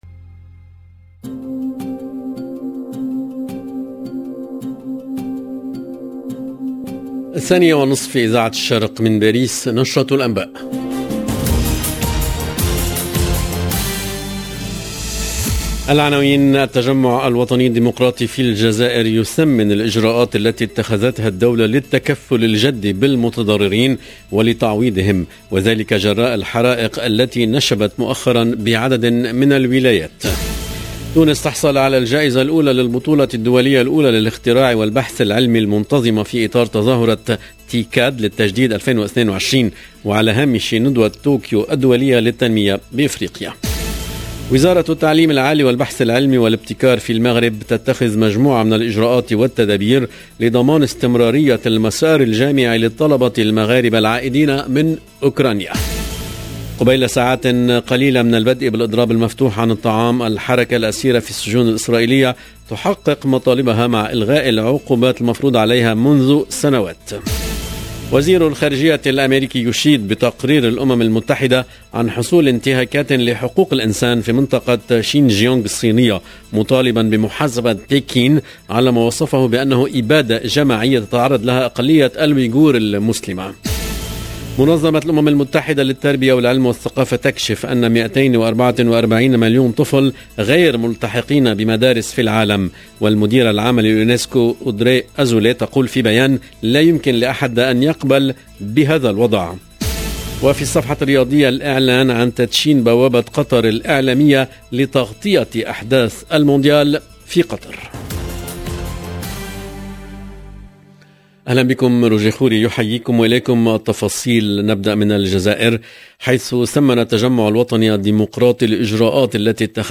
LE JOURNAL EN LANGUE ARABE DE LA MI-JOURNEE DU 2/09/22